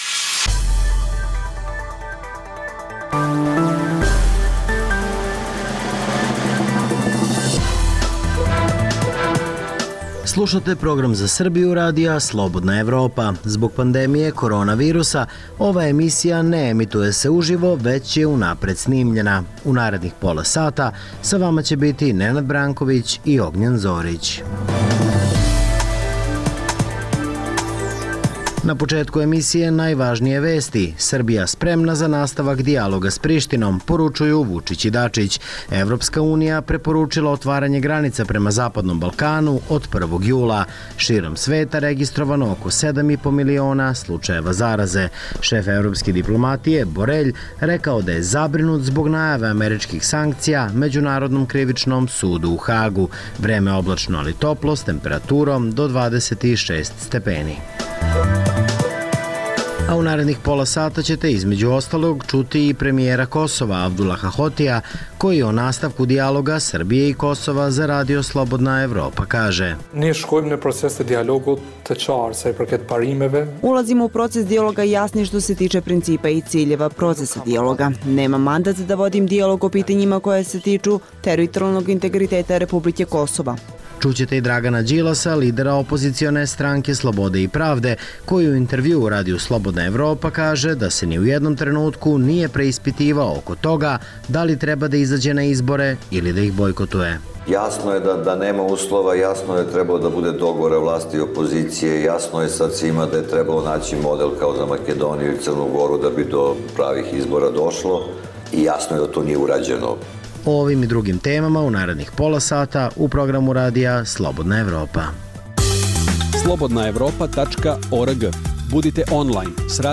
Možete čuti šta premijer Kosova Avdulah Hoti kaže o nastavku dijaloga Srbije i Kosova. Čućete i Dragana Đilasa, lidera opozicione Stranke slobode i pravde, koji u intervjuu za Radio Slobodna Evropa kaže da se ni u jednom trenutku nije preispitivao oko toga da li treba da izađe na izbore ili da ih bojkotuje